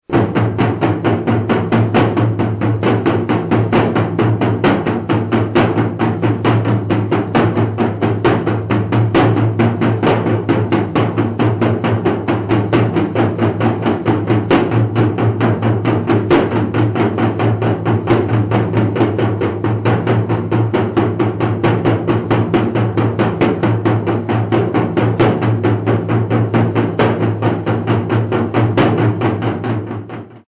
Trommelstück mit 2 nepalesischen Schamanentrommeln
03twodrums30sec.mp3